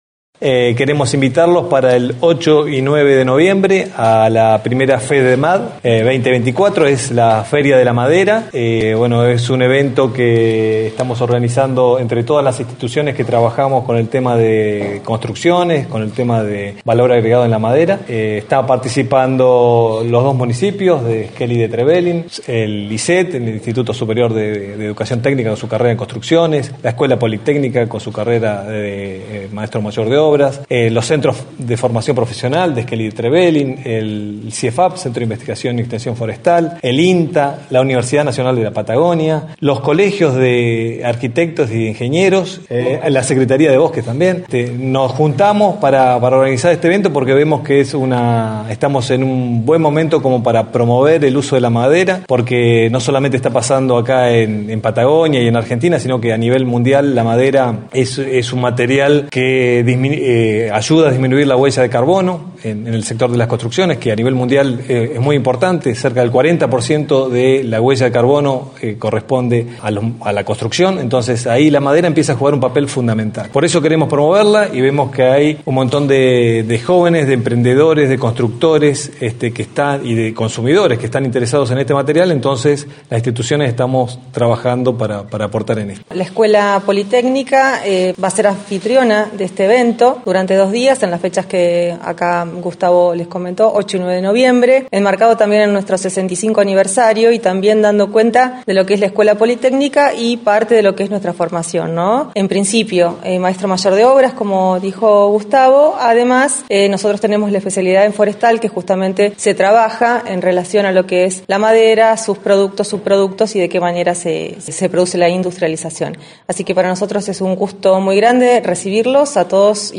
En conferencia de prensa